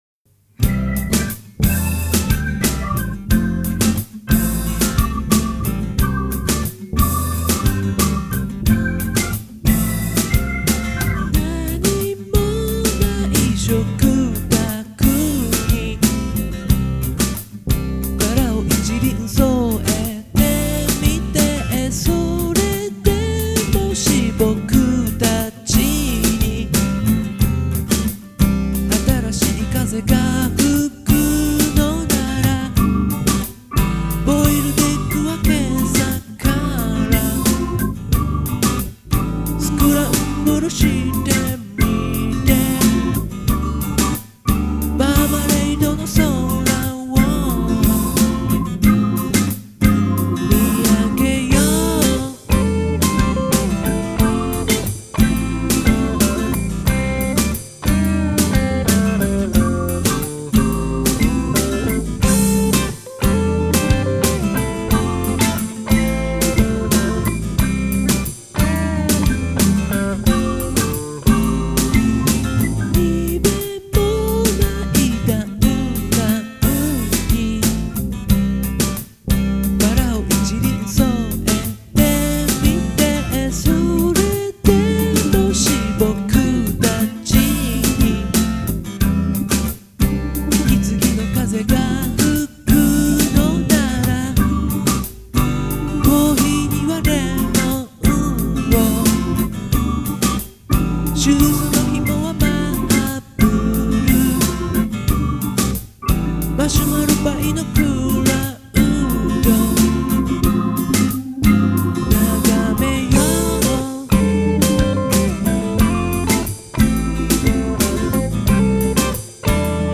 オリジナル音源のMP3公開　〜CD未収録の宅録音源〜